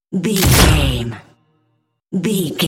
Dramatic drum hit electricity debris
Sound Effects
Atonal
heavy
intense
dark
aggressive
hits